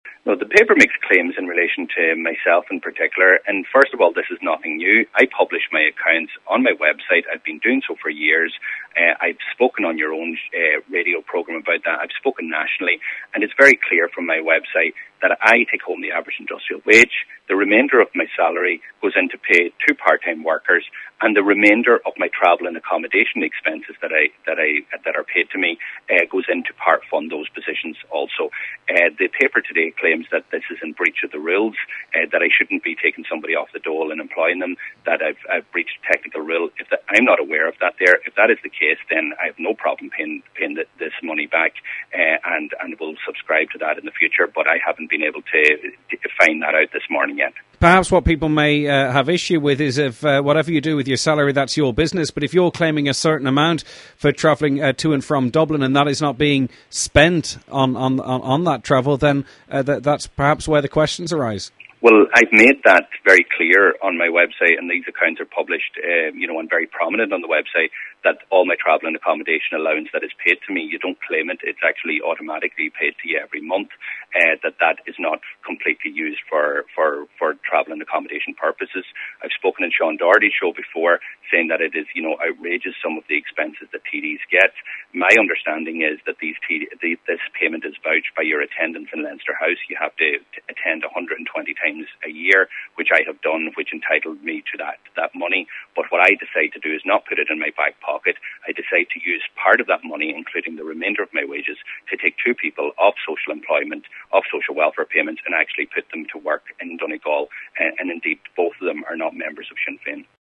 Deputy Doherty says he wasn’t aware that was the case: